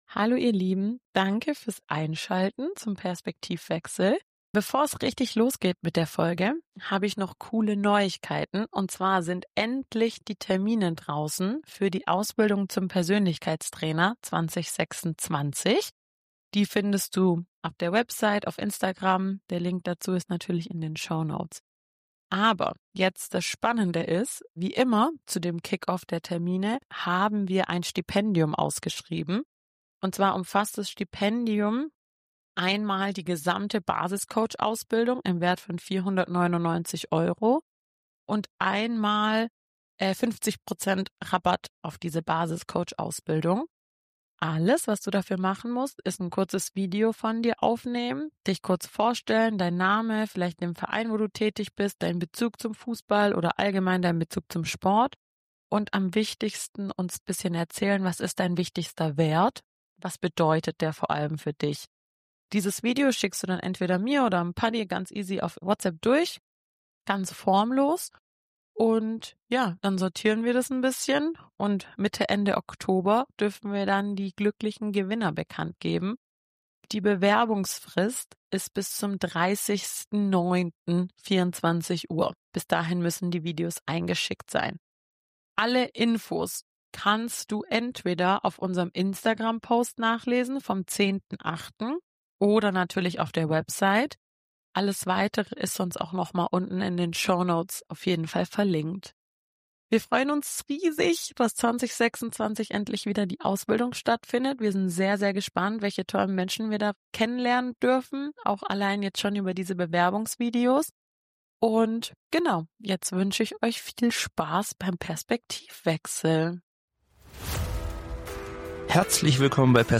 #4 Im Gespräch mit: Dominik Klein ~ Perspektivwechsel Mensch. Trainer. Vorbild. Podcast
In unserer neuen Folge begrüßen wir Dominik Klein – Handball-Weltmeister, Olympiateilnehmer und ehemaliger Profi.